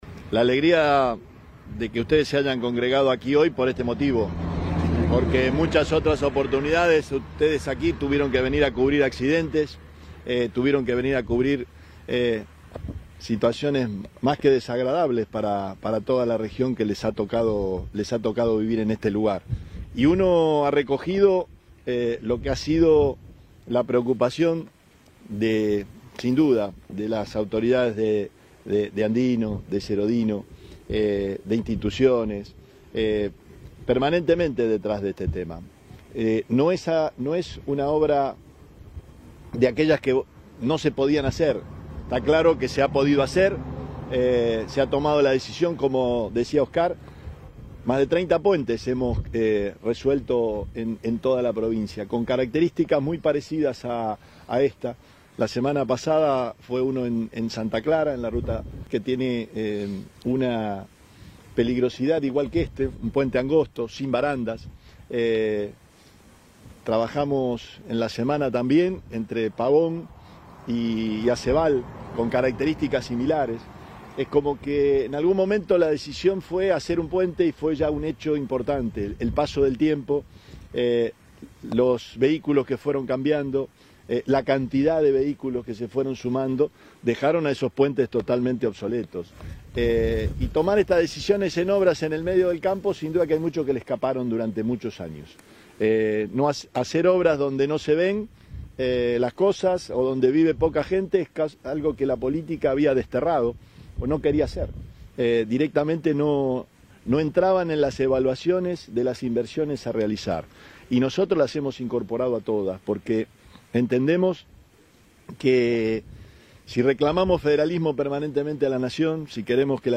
Dicho anuncio fue realizado por el mandatario en la inauguración de las obras de ensanche del puente sobre el canal Serodino, ubicado 6,4 kilómetros al oeste de la autopista Rosario-Santa Fe, departamento Iriondo.
Declaraciones Perotti